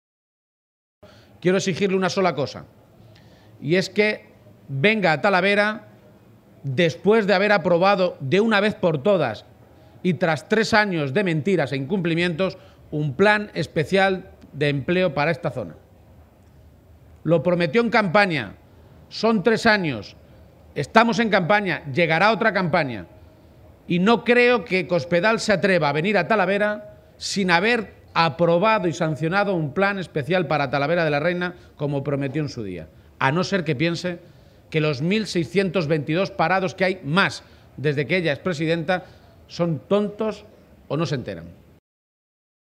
García-Page se pronunciaba de esta manera esta mañana en una comparecencia ante los medios de comunicación, durante su visita a las Ferias de Mayo de Talavera.
Cortes de audio de la rueda de prensa